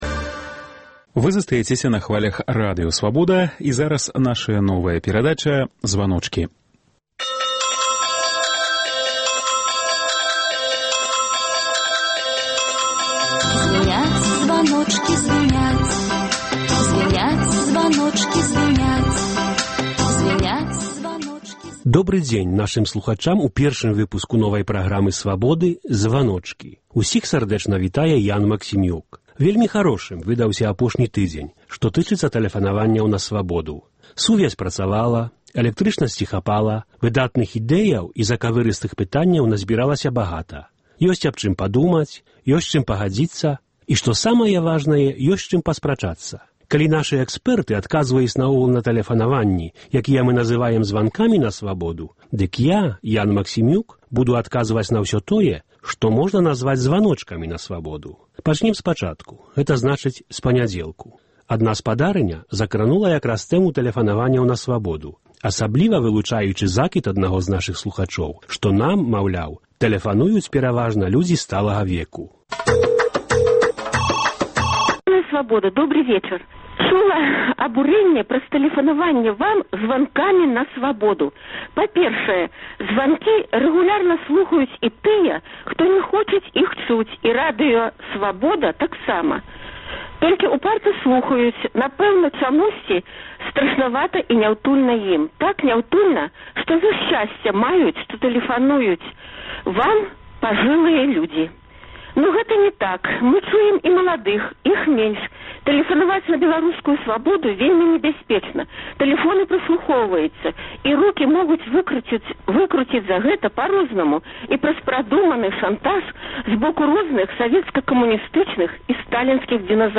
Вось праблемы, якія хвалююць нашых слухачоў у званках на "Свабоду".